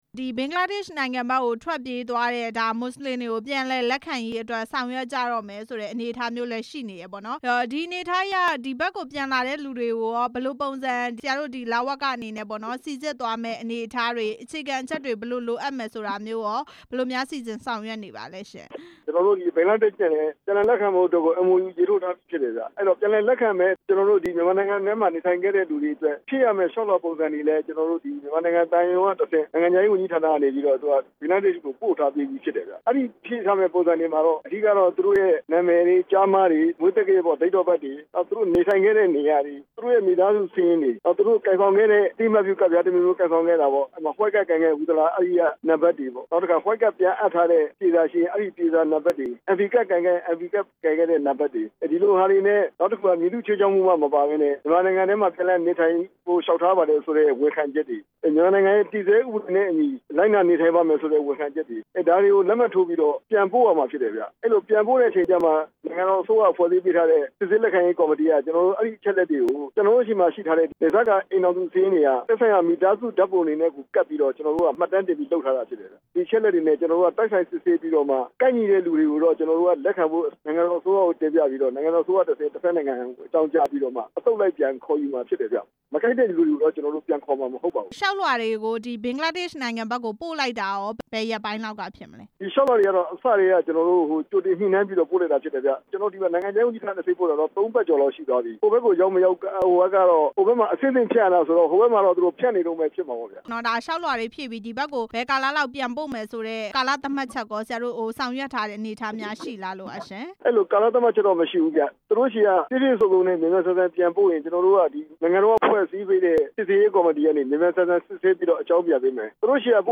ဒုက္ခသည်တွေ လျှောက်ထားရမယ့် လျှောက်လွှာပုံစံအကြောင်း မေးမြန်းချက်